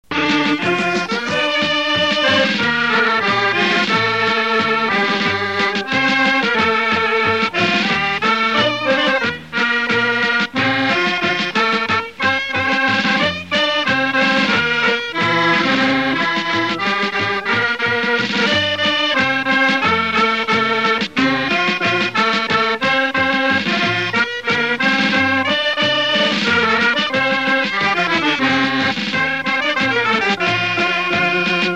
Résumé instrumental
Pièce musicale inédite